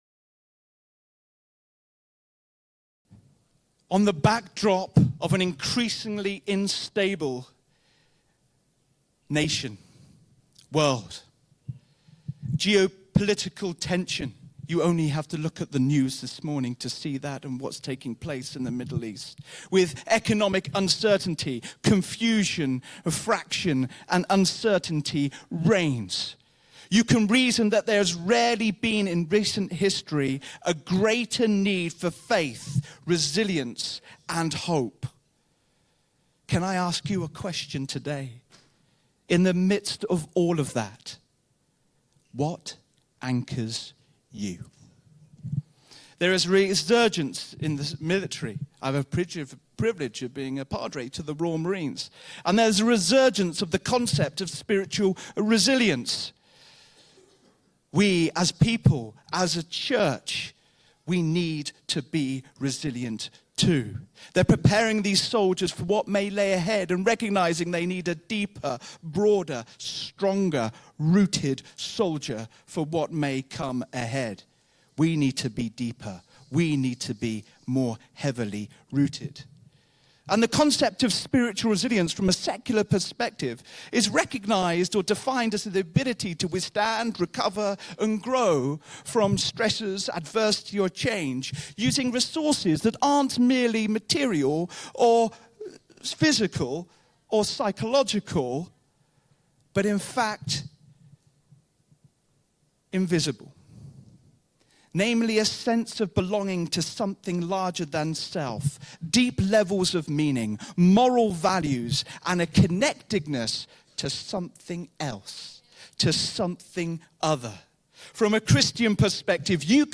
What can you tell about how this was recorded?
[First part of the message removed due to poor audio].